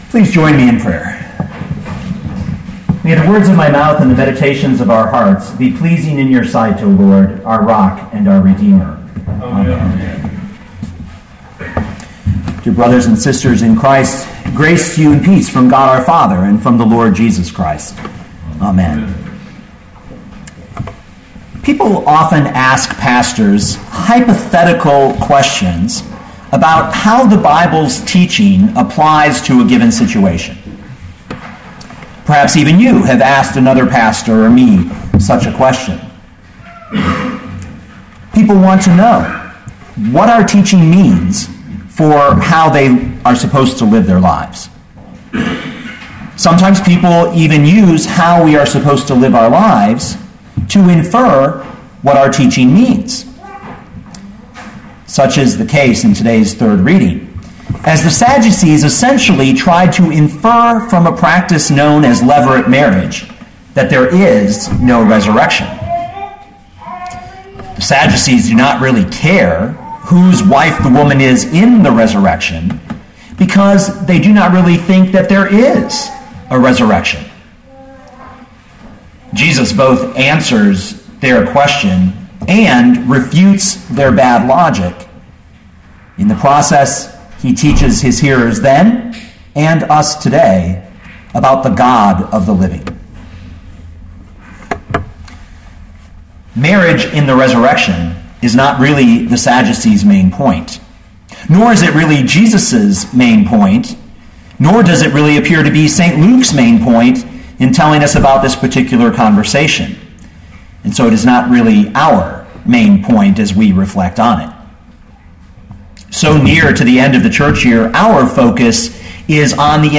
2013 Luke 20:27-40 Listen to the sermon with the player below, or, download the audio.